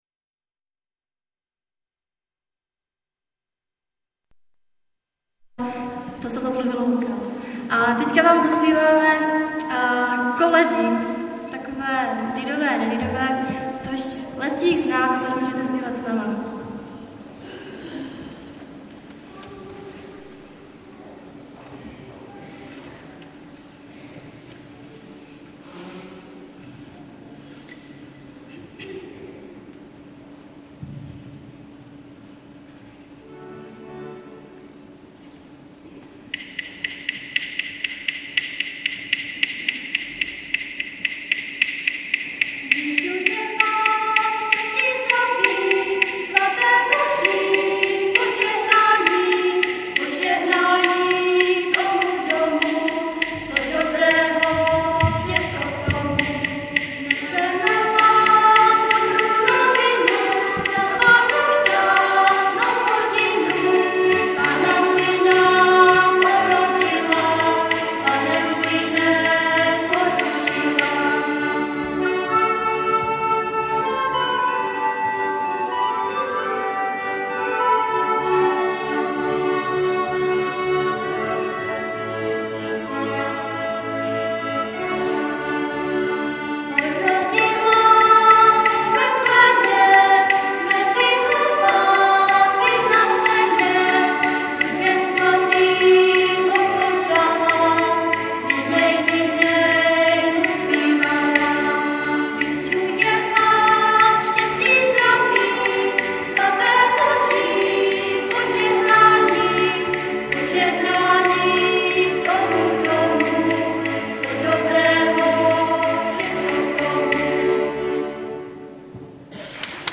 ADVENTNÍ KONCERT
Březová … kostel sv. Cyrila a Metoděje ... neděla 9.12.2007
... pro přehrátí klepni na názvy písniček...bohužel ně skomírala baterka v MD ...